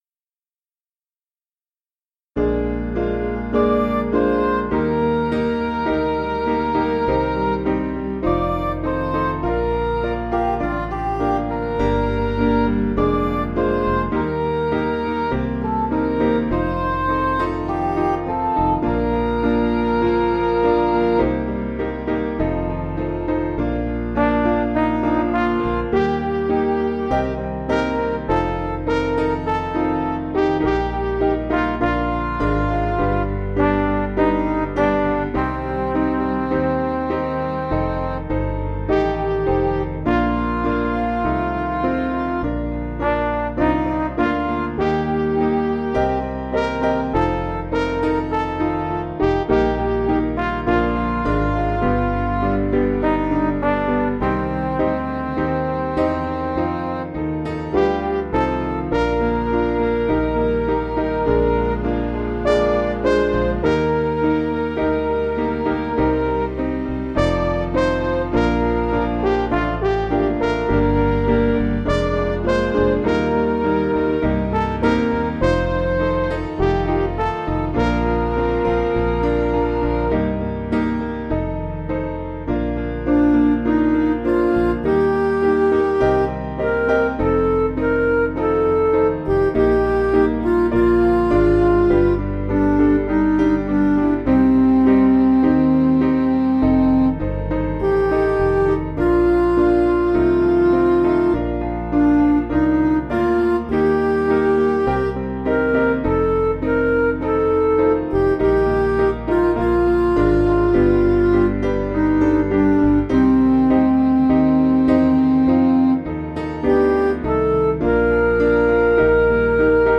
Piano & Instrumental
(CM)   4/Bb
Quieter Version   496.4kb